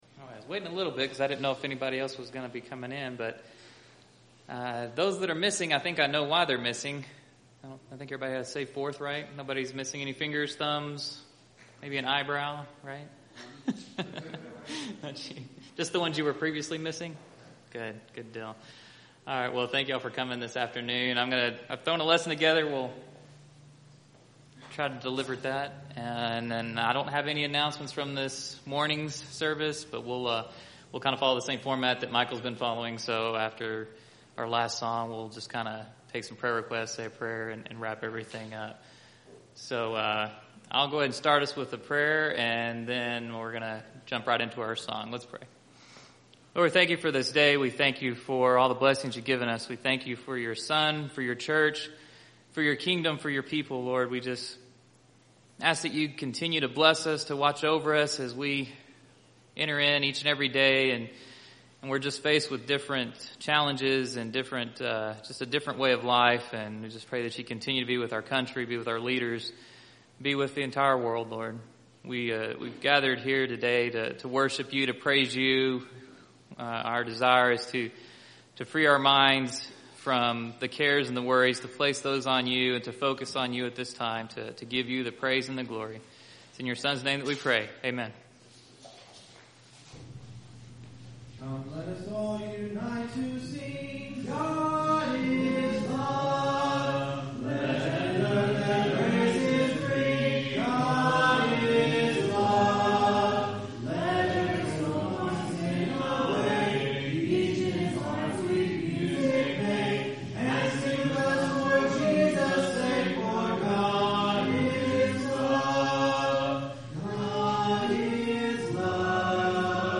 July 5th – Sermons